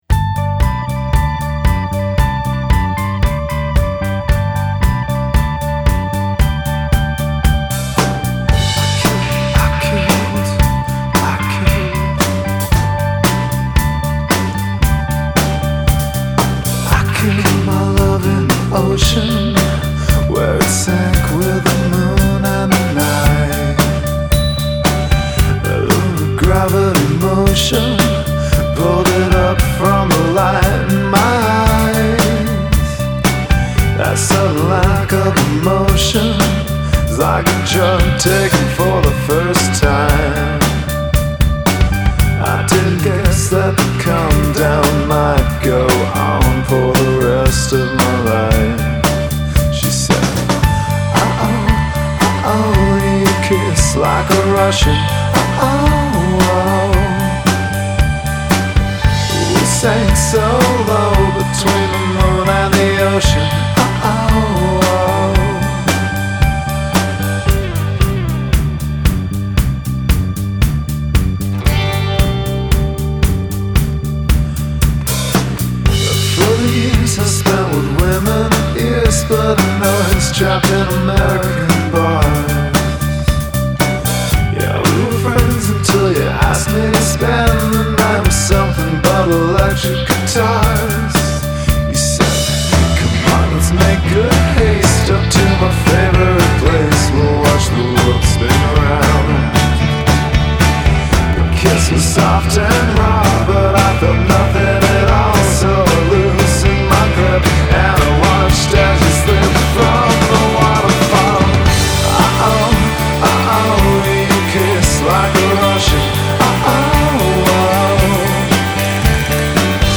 thoughtful, tuneful, sophisticated pop